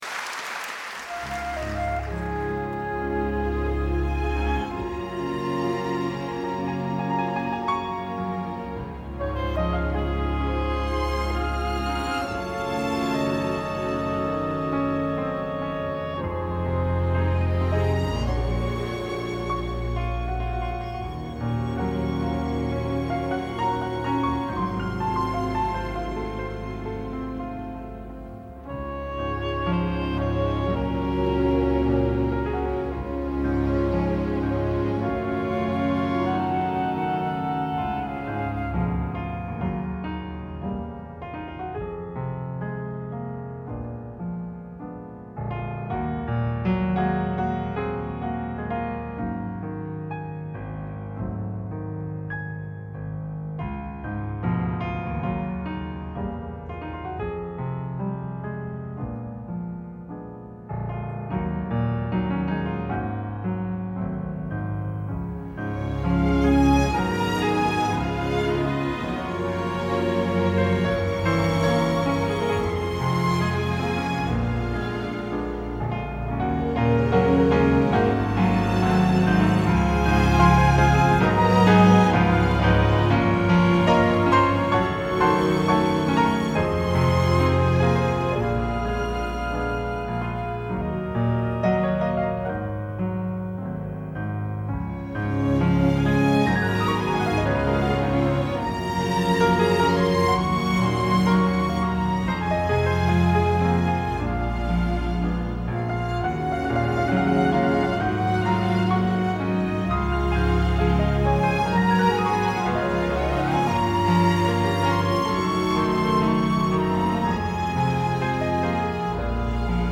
This is the kind of thing I have to listen to carefully to make sure everything is right: no misplaced notes, wrong chords, etc. The mp3 below is a combination of a midi-generated orchestration from Finale that is sort of synced against my rough recording. You have to use your imagination with this because it is so rough. There are no dynamics in here at all and the midi does not quite match my rubato.
This is a string and woodwind orchestration. Rough Midi Mix Piano chart